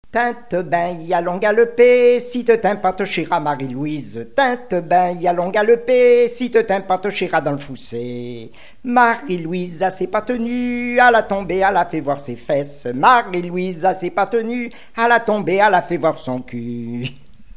Mémoires et Patrimoines vivants - RaddO est une base de données d'archives iconographiques et sonores.
Chants brefs - A danser
Genre brève
Pièce musicale inédite